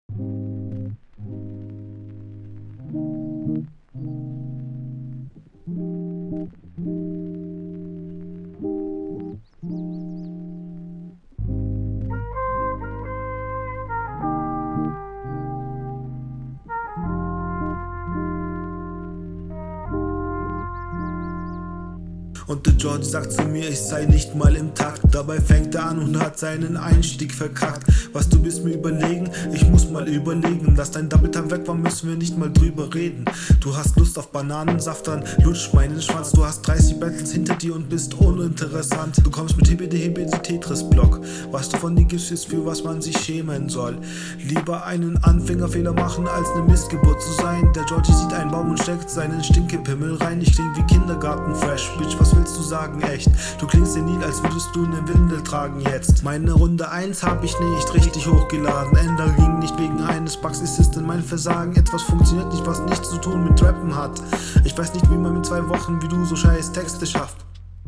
Die Lines sind teilweise echt lustig aber triffst nicht immer den Takt leider.